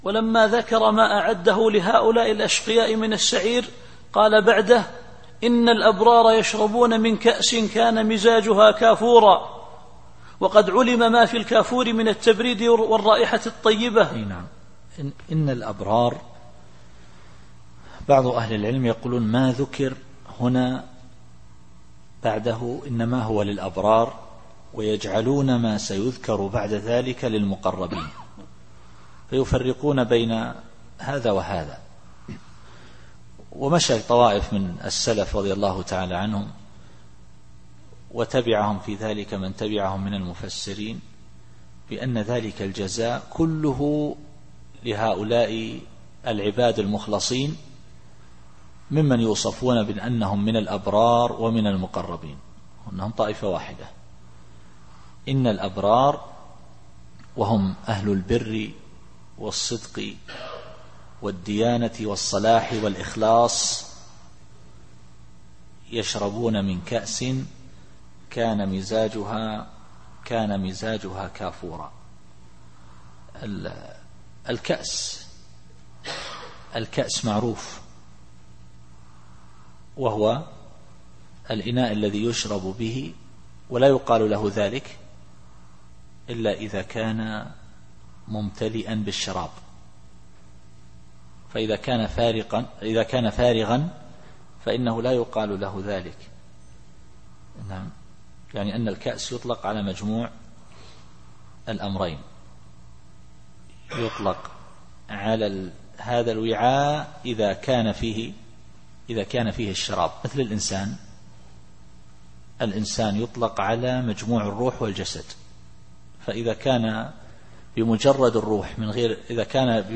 التفسير الصوتي [الإنسان / 6]